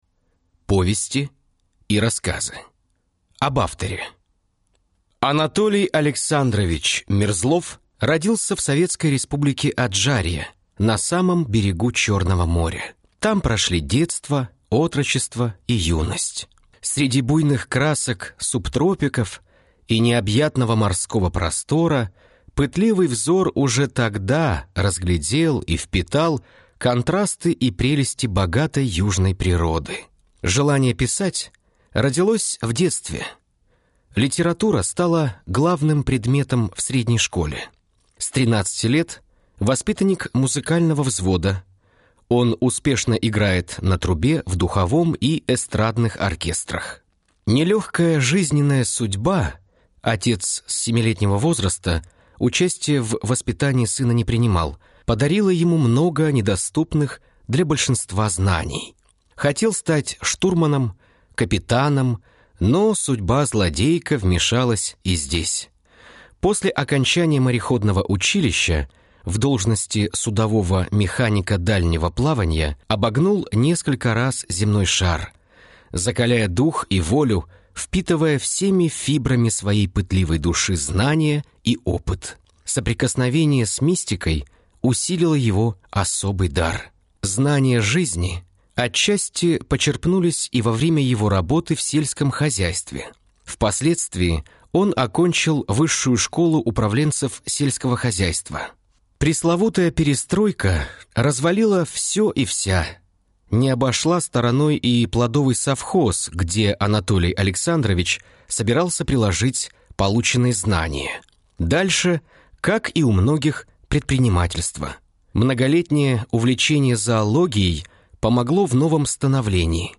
Аудиокнига Платановая аллея | Библиотека аудиокниг
Прослушать и бесплатно скачать фрагмент аудиокниги